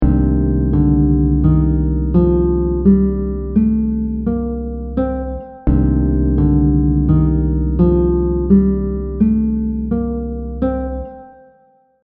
The audio examples will repeat the scale over a chord so you can get a better grasp of the sound of each mode.
• Mood / emotion: pensive, curious 🤔
• Characteristic note: natural 6th
You can think of the Dorian scale as a minor scale with a natural 6th degree.
C Dorian scale audio example